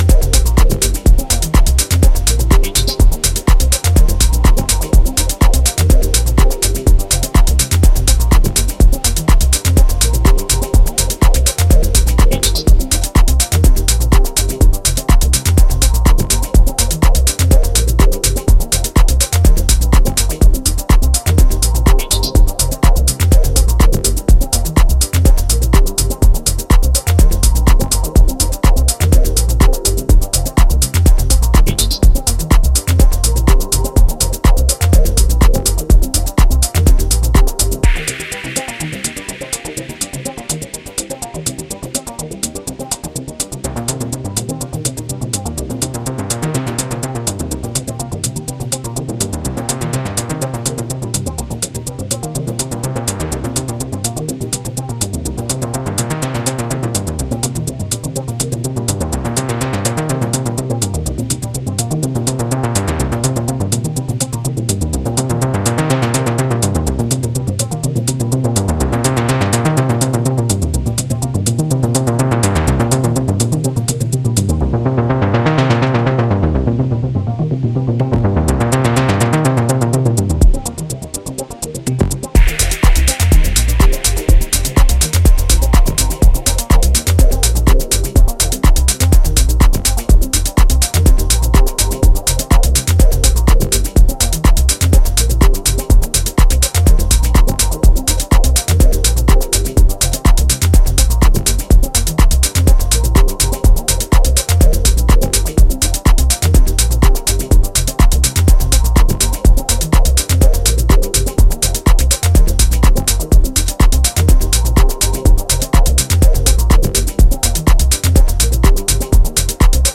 futuristic deep tech sounds with lively bass